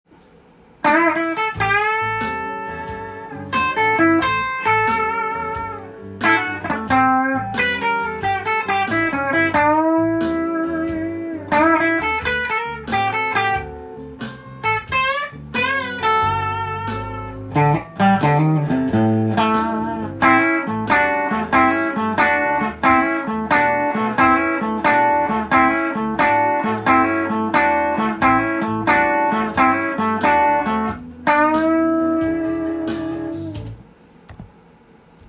今日は、ブルースギター【手癖まみれのレジェンドソロ】オリジナルtab譜
そのギタリスト達が過去に弾いたフレーズのみで構成されたギターソロを
カラオケ音源にあわせて譜面通り弾いてみました。
意外といける感じです。
teguse.furezu.blues_.solo_.m4a